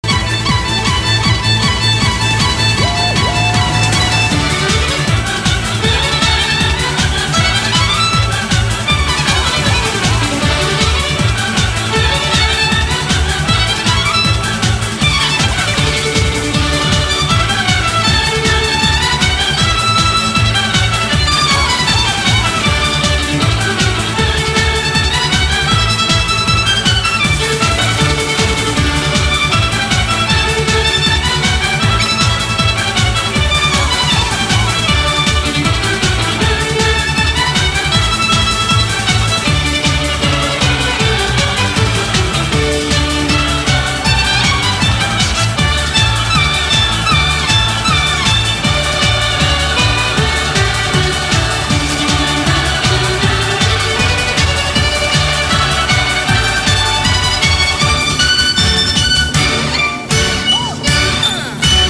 • Качество: 256, Stereo
Классная мелодия в современном исполнении